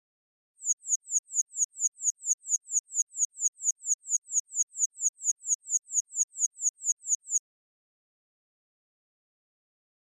Flash Alarm High Frequency Tweeting Electronic Alarm